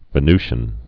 (vĭ-nzhən, -shē-ən, -ny-)